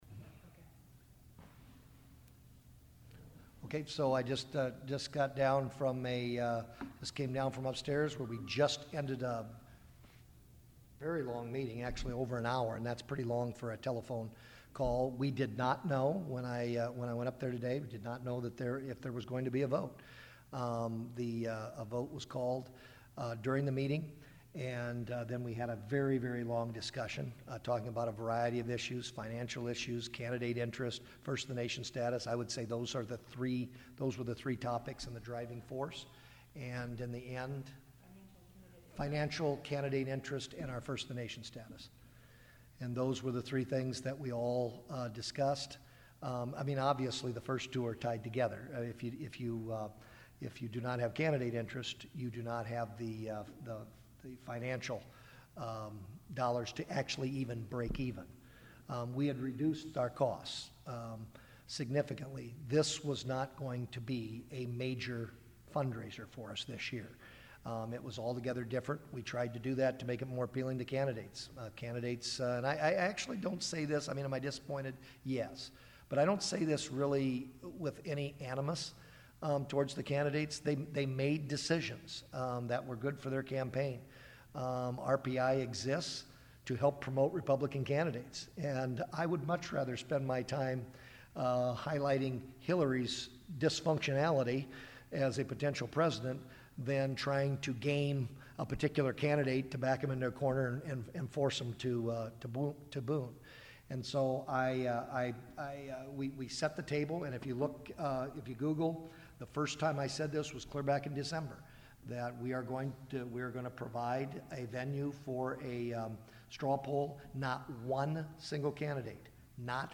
AUDIO of Kaufmann speaking with Radio Iowa & Des Moines Register reporters.